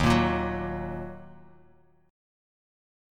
D#m Chord
Listen to D#m strummed